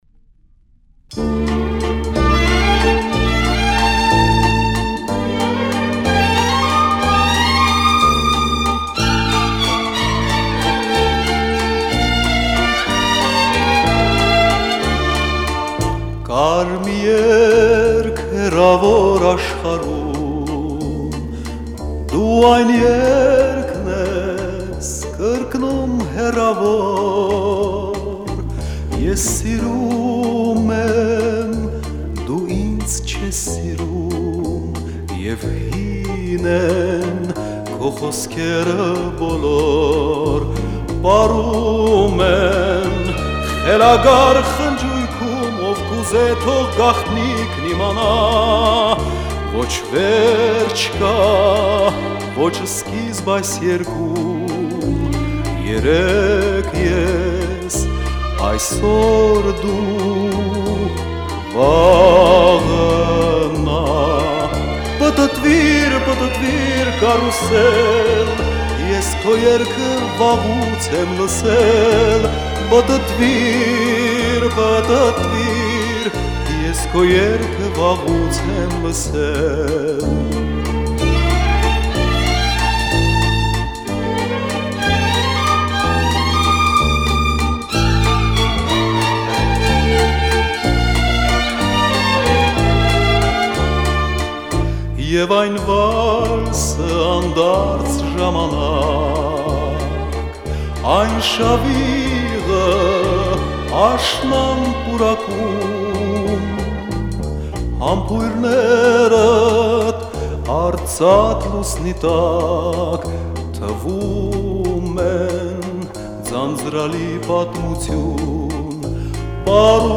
Нашел запись с пластинки, но оранжировка отличается...